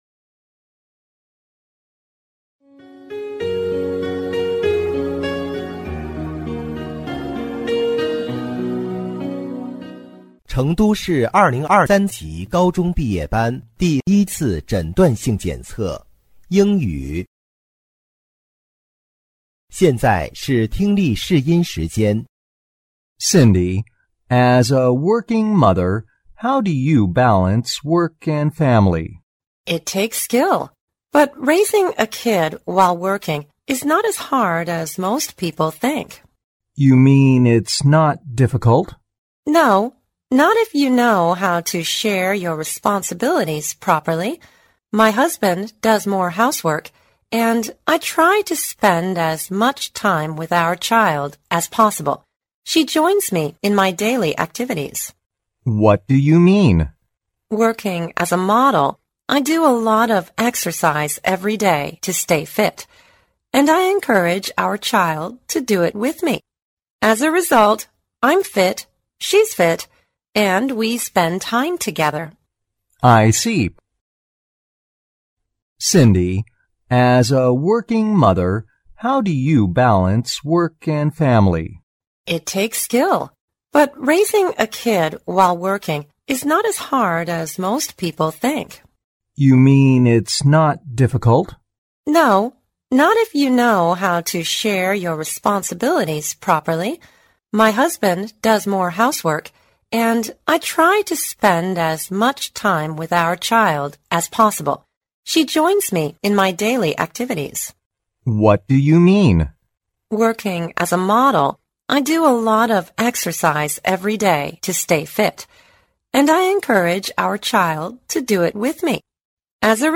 2026届成都一诊英语听力.mp3